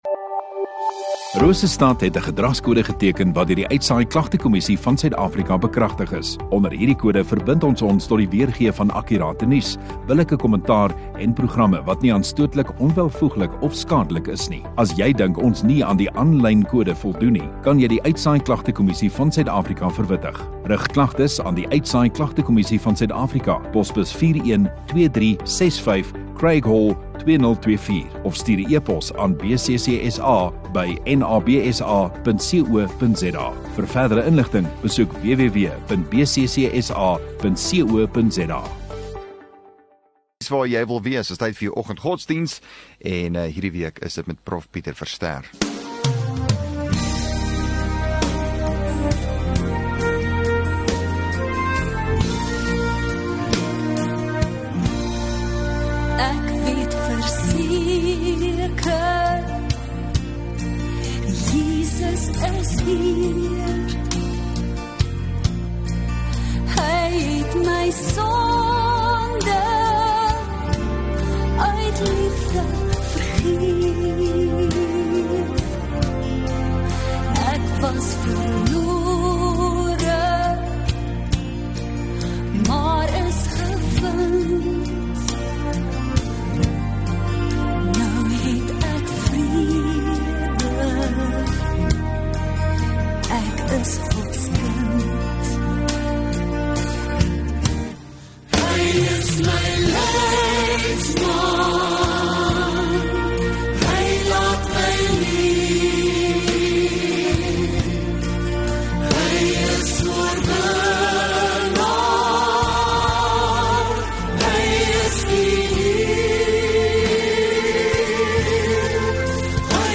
28 Mar Vrydag Oggenddiens